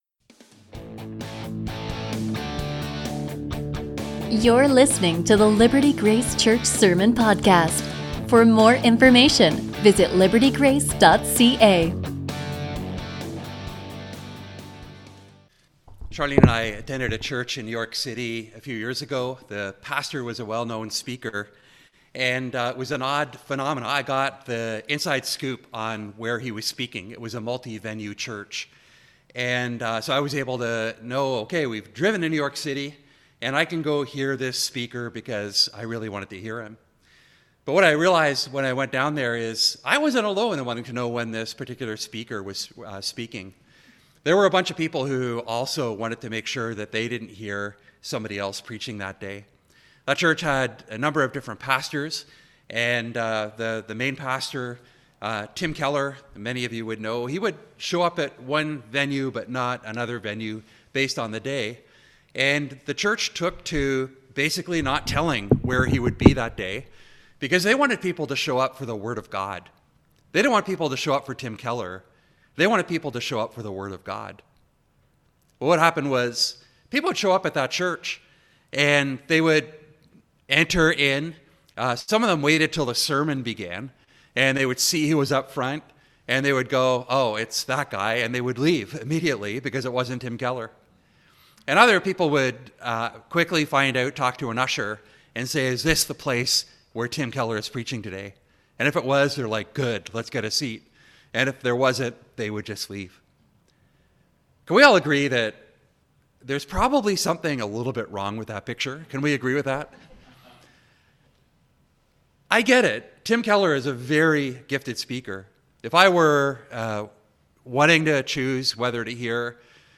A sermon from 1 Corinthians 3:1-4:21